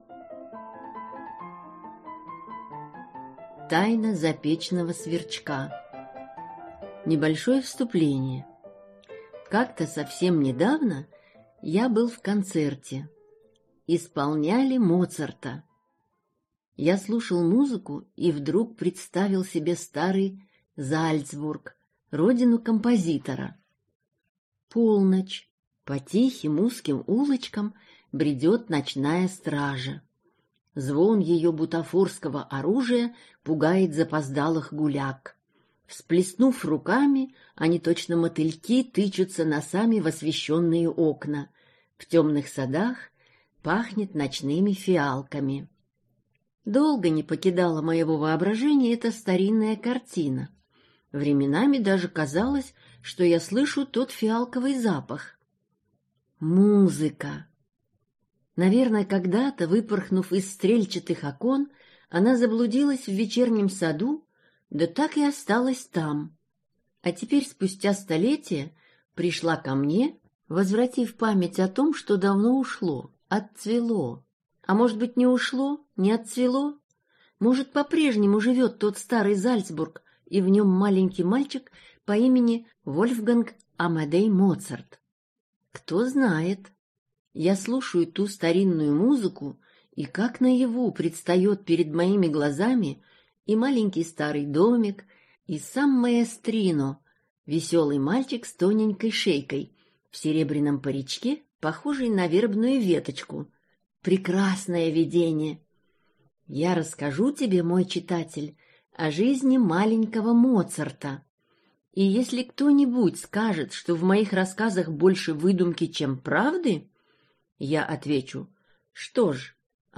Тайна запечного сверчка - аудиосказка Геннадия Цыферова - слушать онлайн | Мишкины книжки